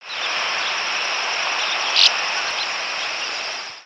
Northern Rough-winged Swallow Stelgidopteryx serripennis
Flight call description A low, buzzy, slightly rising "bjjeet", given singly or repeated in rapid succession.
Bird in flight.
Similar species Similar to Dickcissel but longer, more liquid, and more rapidly repeated.